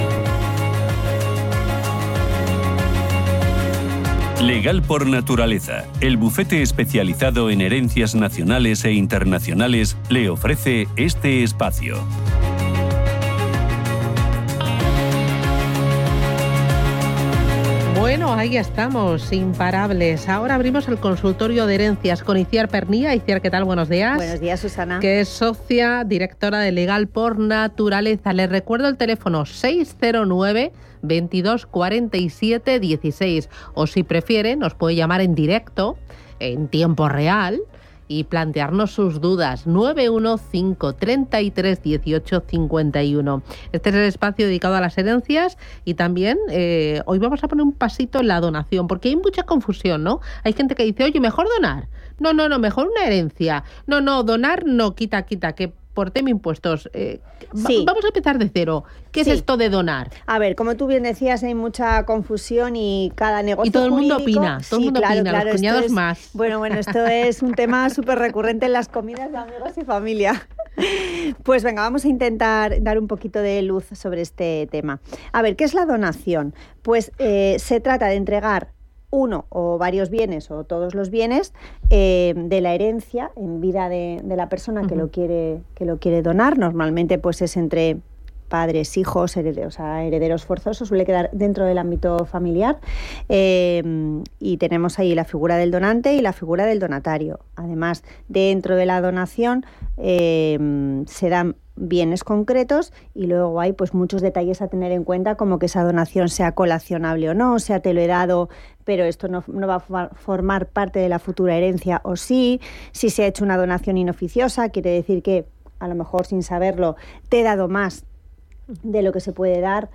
Puede escuchar el consultorio de herencias completo pinchando aquí.